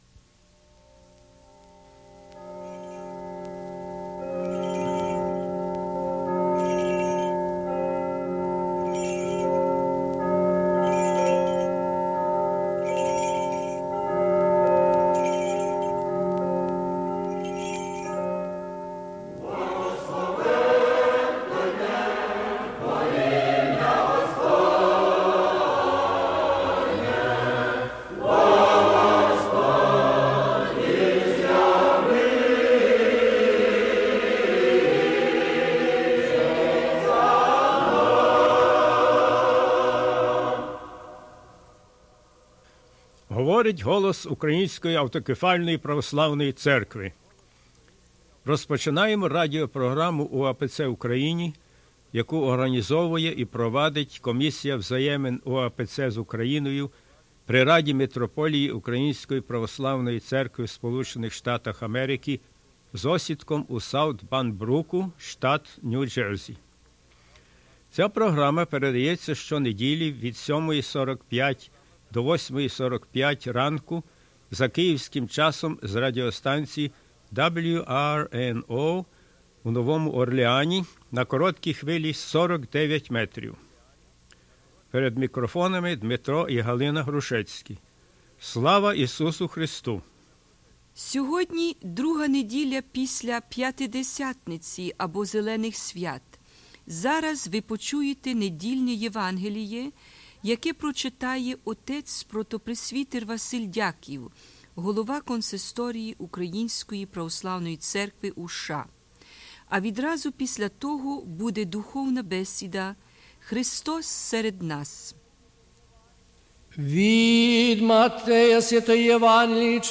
Gospel reading and "Spiritual Discussion"
Program announcement